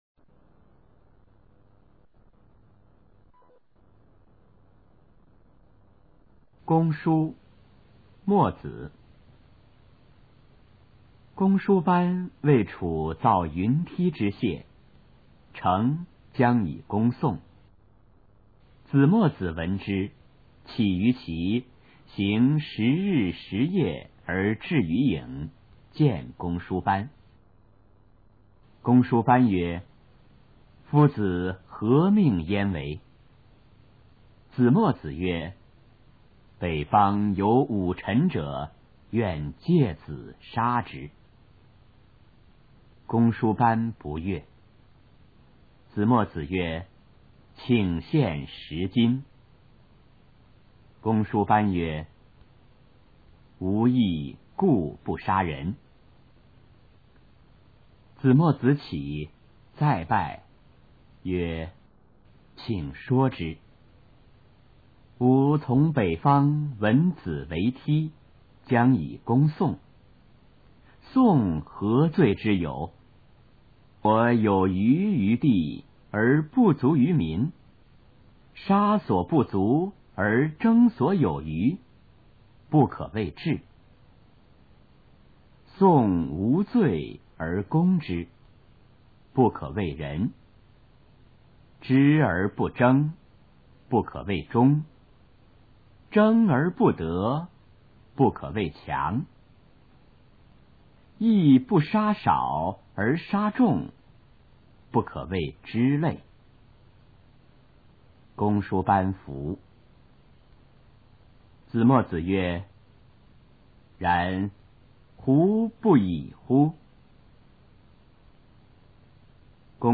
首页 视听 语文教材文言诗文翻译与朗诵 初中语文九年级下册
《公输》原文和译文（含在线朗读）